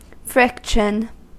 Ääntäminen
Ääntäminen US : IPA : [ˈfrɪk.ʃən] Tuntematon aksentti: IPA : /ˈfɹɪkʃən̩/ Haettu sana löytyi näillä lähdekielillä: englanti Määritelmät Substantiivit The rubbing of one object or surface against another.